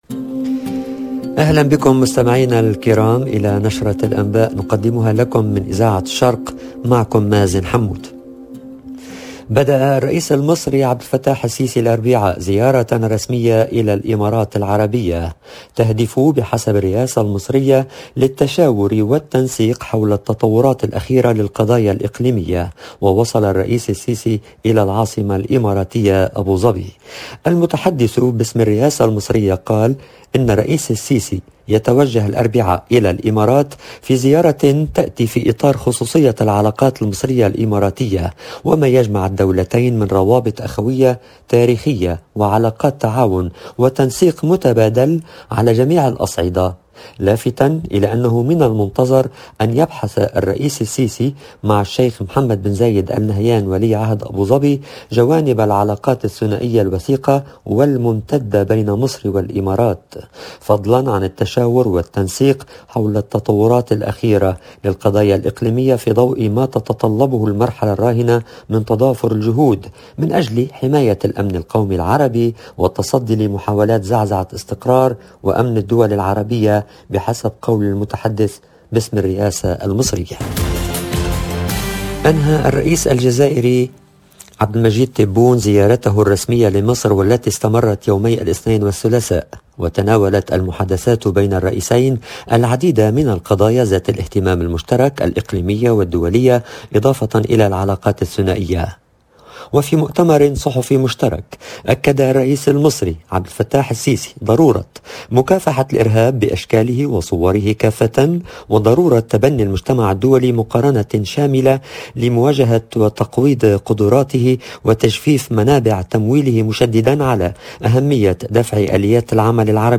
LE JOURNAL DU SOIR EN LANGUE ARABE DU 26/01/22